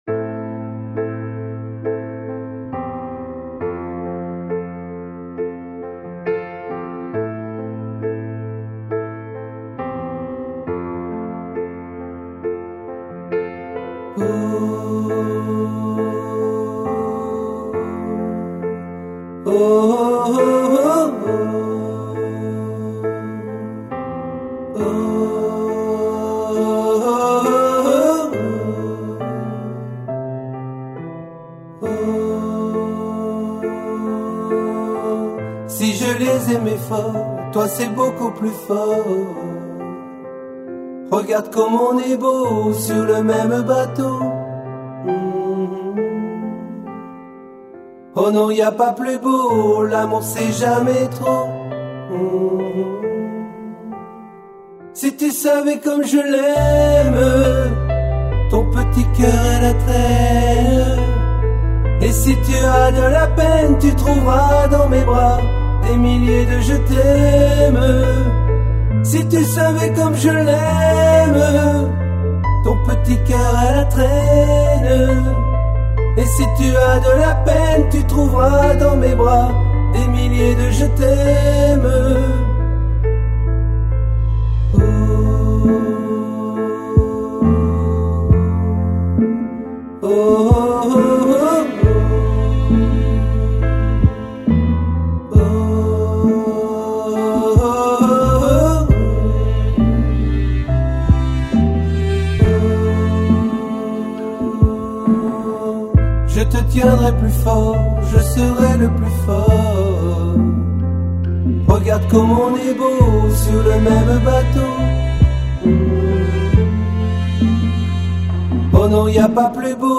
Ténor